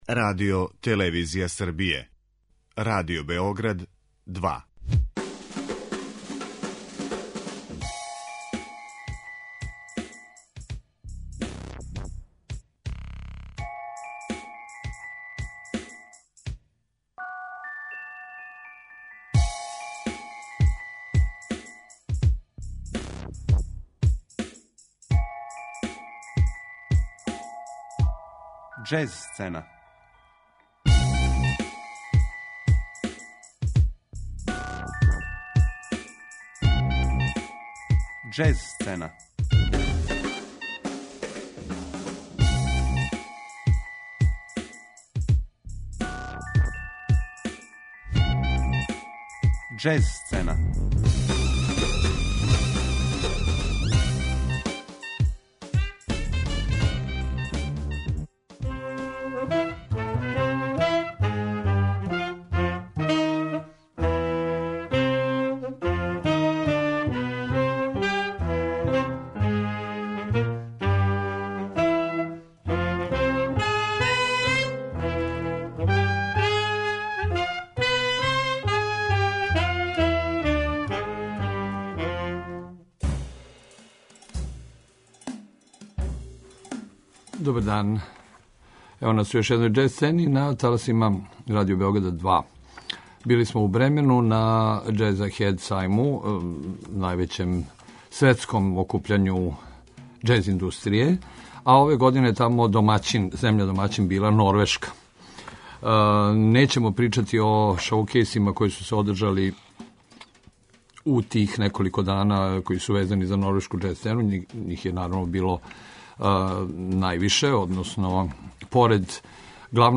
Норвешка џез сцена
У новом издању Џез сцене Радио Београда 2 представљамо савремени норвешки џез. Уметници из Норвешке успешно комбинују џез израз са скандинавским фоклором, европском класиком, роком, психоделијом, трип-хопом и електроником, трасирајући „нове џез концепције", како је то деведесетих година назвао један од њихових најуспешнијих извођача, клавијатуриста и продуцент Буге Веселтофт.